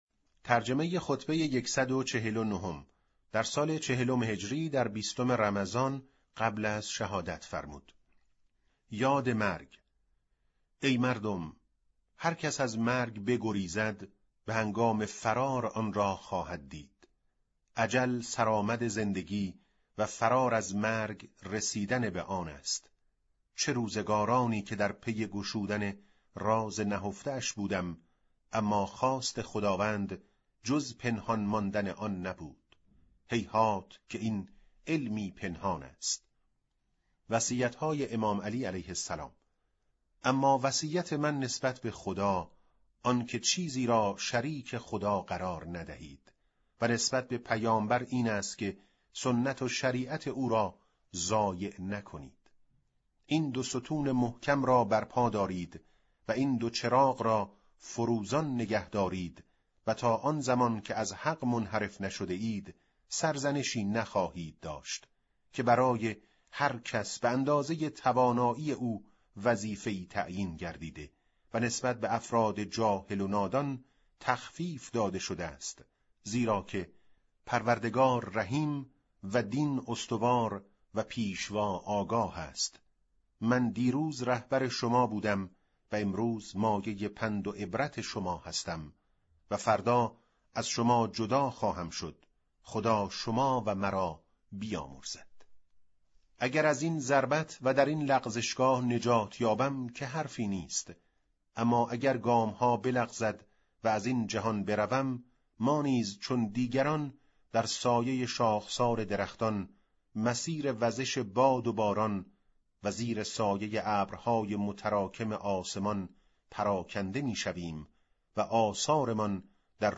به گزارش وب گردی خبرگزاری صداوسیما؛ در این مطلب وب گردی قصد داریم، خطبه شماره ۱۴۹ از کتاب ارزشمند نهج البلاغه با ترجمه محمد دشتی را مرور نماییم، ضمنا صوت خوانش خطبه و ترجمه آن ضمیمه شده است: